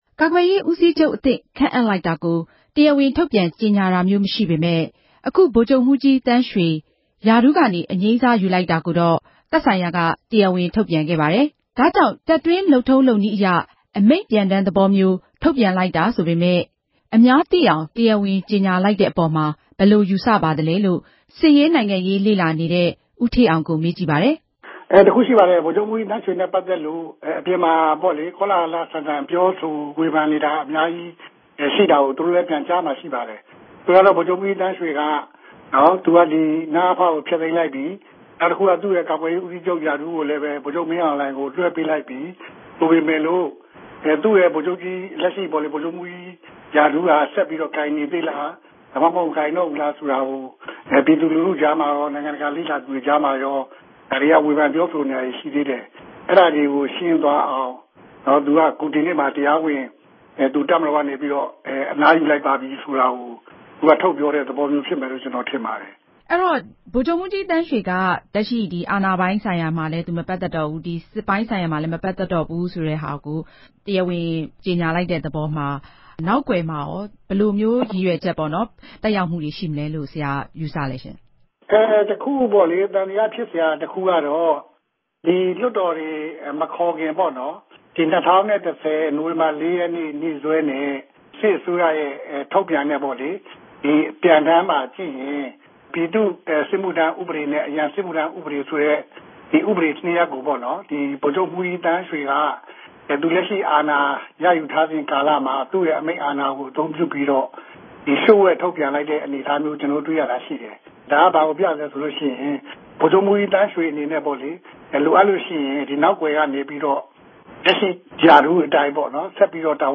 သတင်းတင်ပြချက်